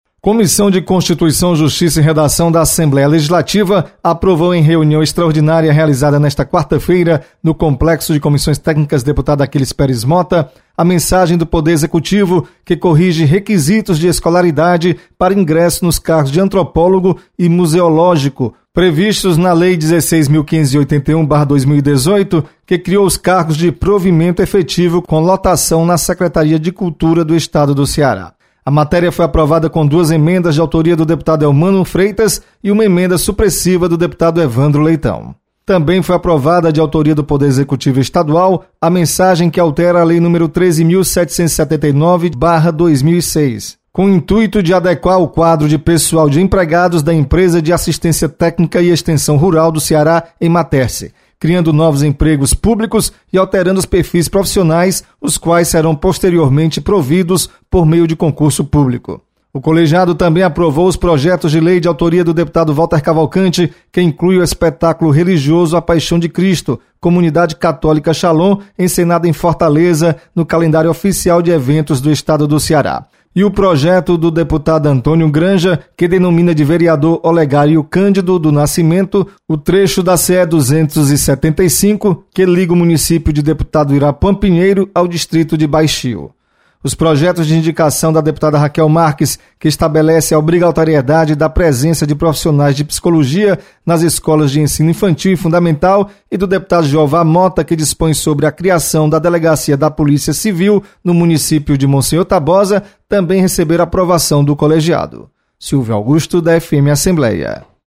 Comissão de Constituição, Justiça e Redação realiza, nesta quinta, reunião para limpar a pauta e encaminhar ao Plenário para votação. Repórter